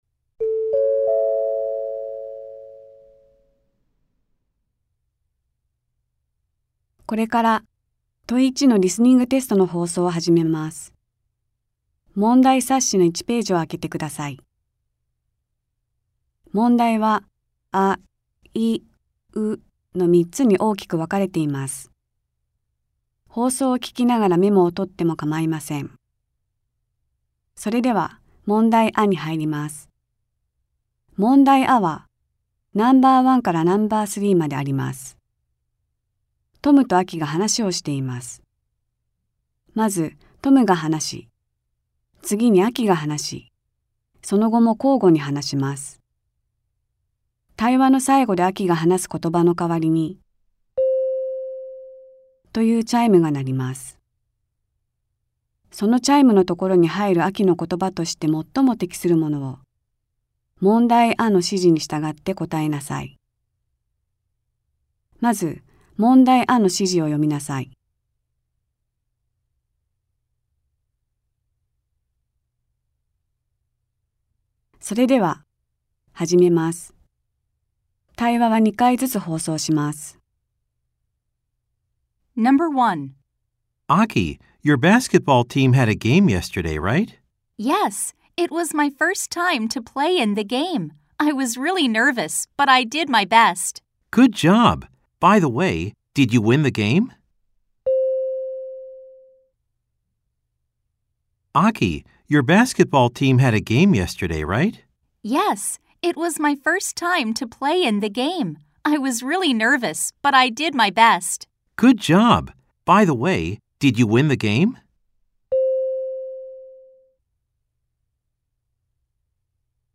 ・リスニング音源（MP3：8,439KB）